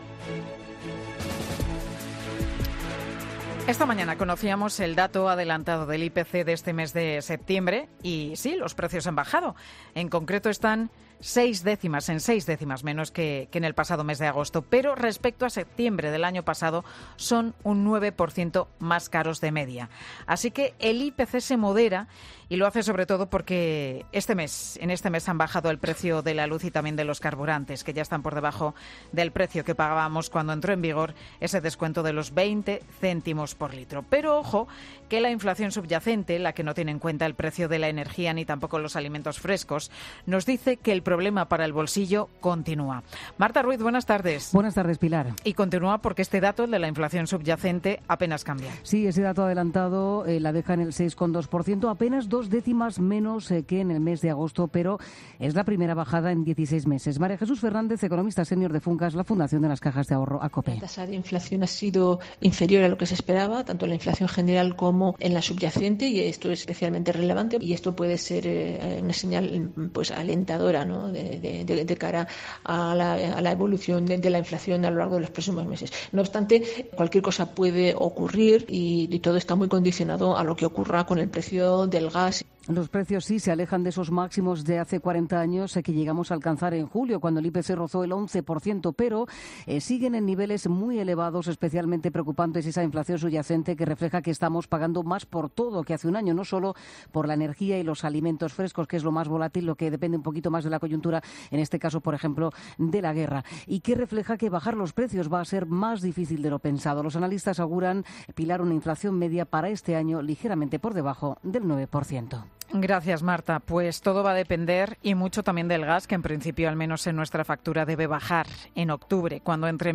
La bajada de la inflación, según Funcas, es una señal alentadora. Crónica